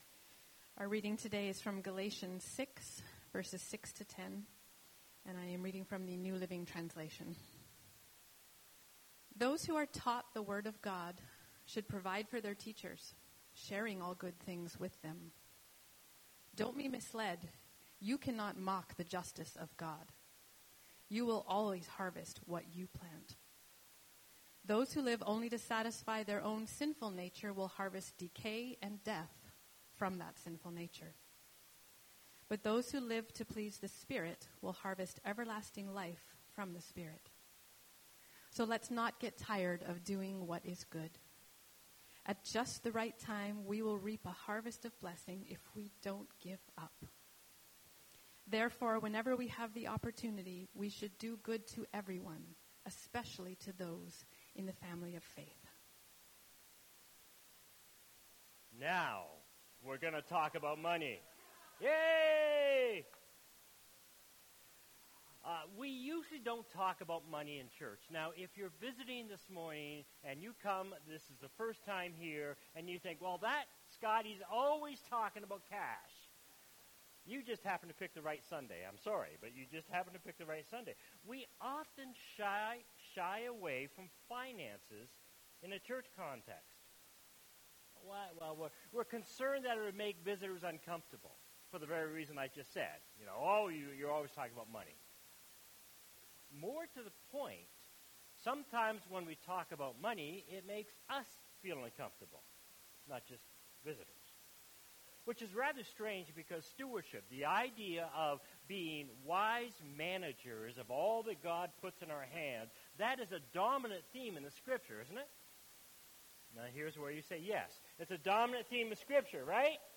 Shaped by the Hands of God Knit Together Shaped by the Hands of God Phil 1:1-11 Guest Speaker October 5, 2025 Current Sermon Open Heart.